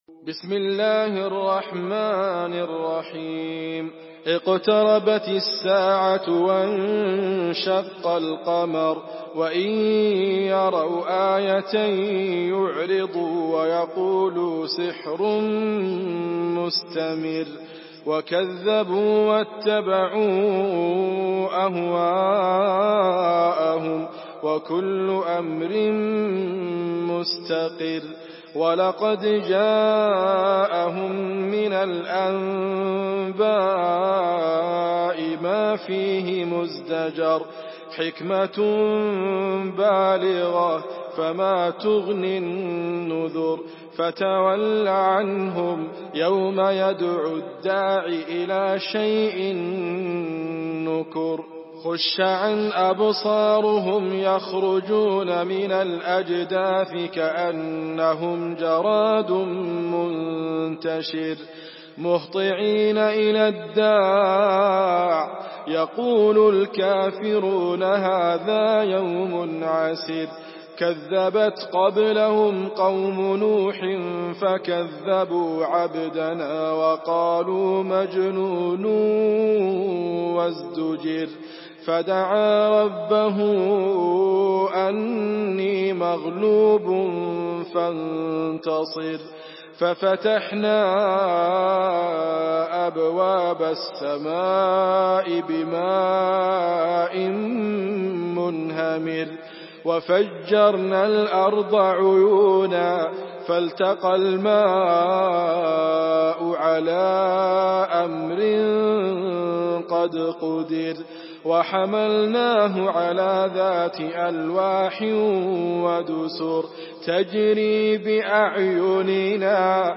Surah আল-ক্বামার MP3 in the Voice of Idriss Abkar in Hafs Narration
Surah আল-ক্বামার MP3 by Idriss Abkar in Hafs An Asim narration.
Murattal Hafs An Asim